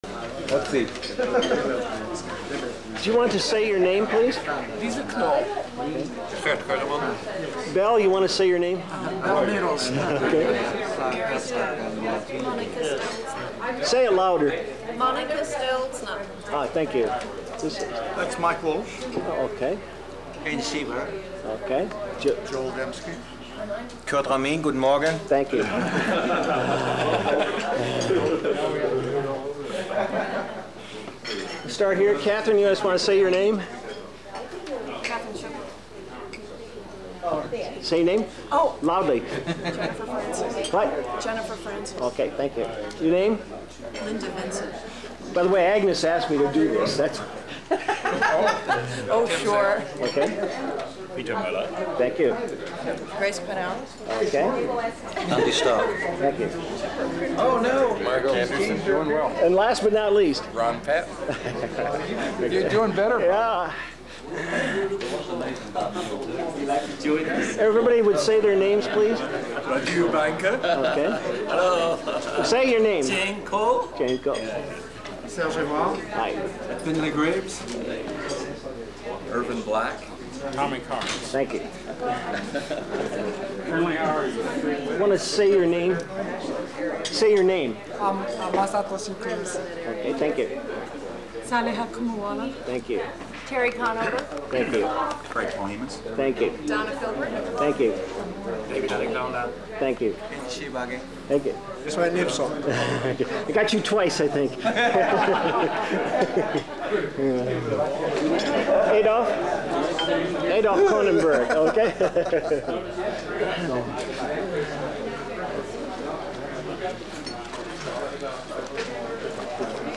The GSAR speakers in Berlin were not wearing microphones, so the only audio captured was through the microphone built into my old Sony camcorder.